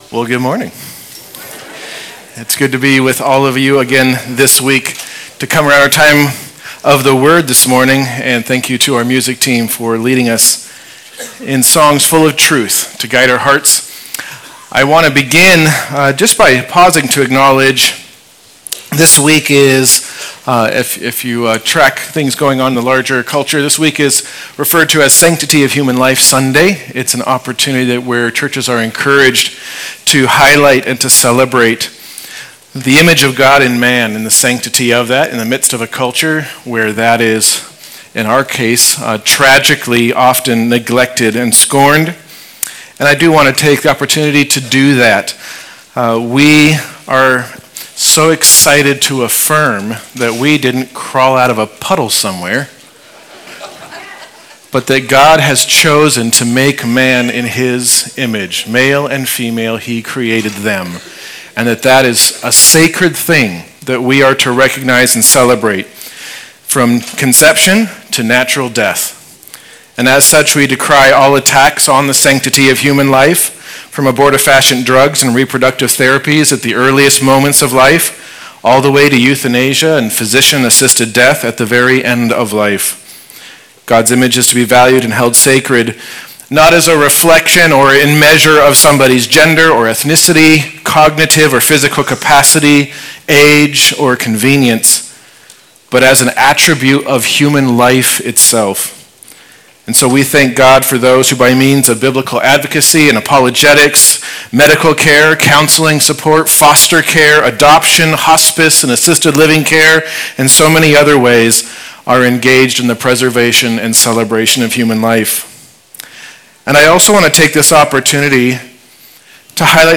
January 18’s Sunday service livestream